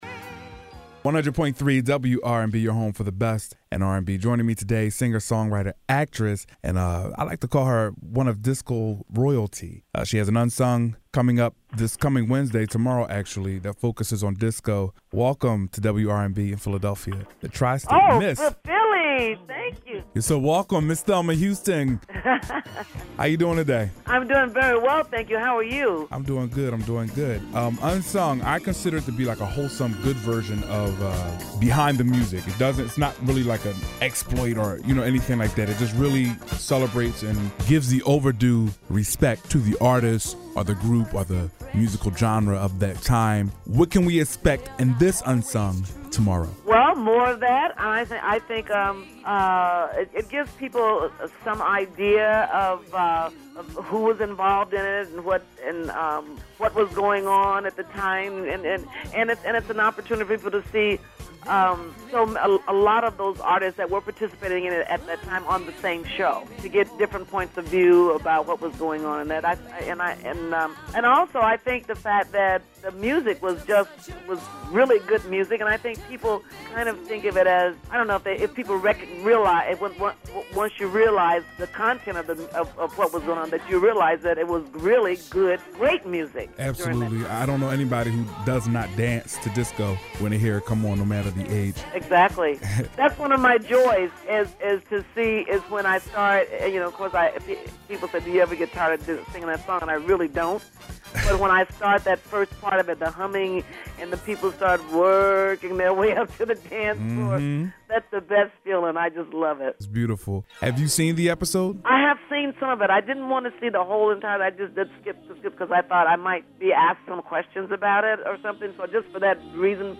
Listen as I chat with disco royalty Thelma Houston about the upcoming unsung disco episode, her fav artist, her Philly connect, & more!